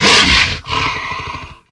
psy_attack_1.ogg